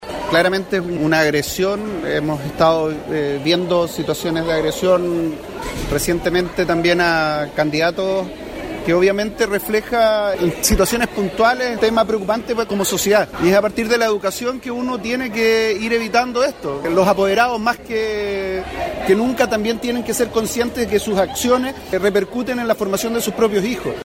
Pablo Baeza, seremi de Educación, se refirió a las agresiones y las comparó con los ataques a algunos candidatos políticos.